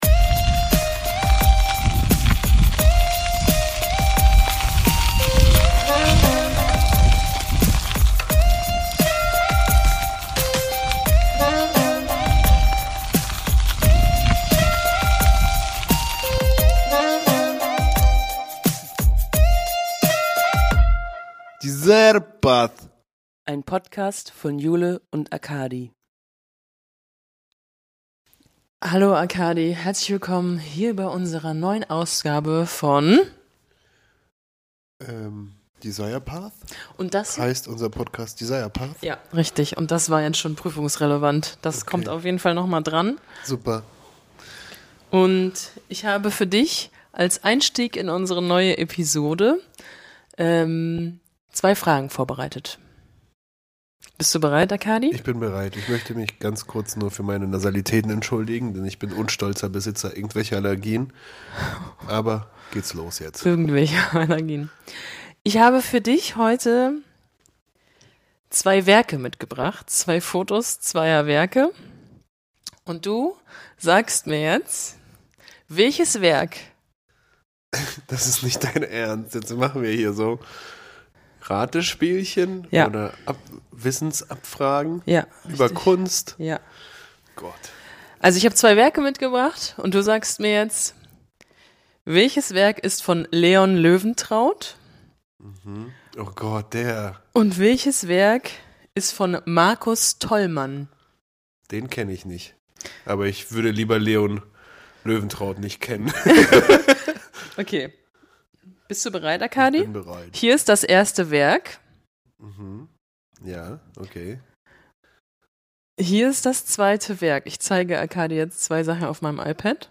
Mitten in der Folge könnt ihr unser erstes Hörspiel genießen, welches euch mitnimmt zu den Anfängen unserer neuen Galerie.